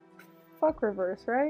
Play, download and share F reverse original sound button!!!!
f-reverse.mp3